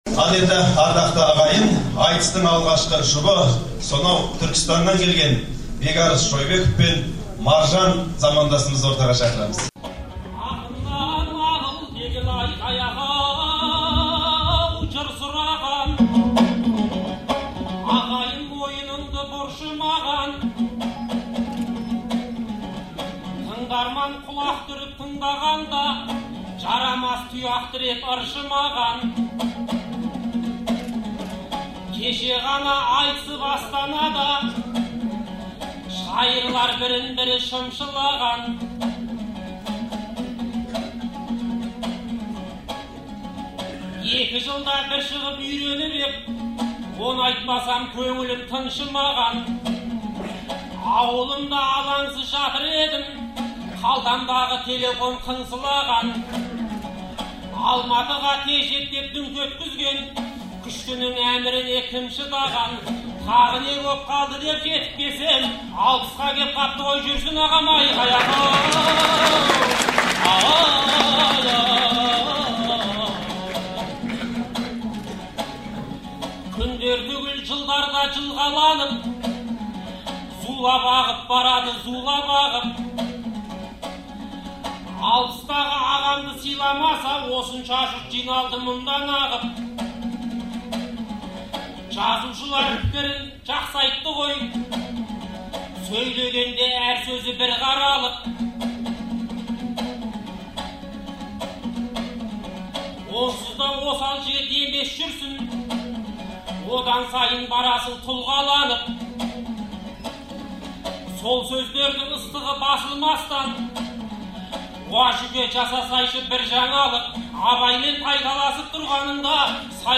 Ақпанның 12 күні Алматыдағы Ғабит Мүсірепов атындағы Жастар мен балалар театрында «Жүрсіннің жүйріктері» атты айтыс болып өтті. Қалың көрерменнің жартысы залға симай сыртта қалды.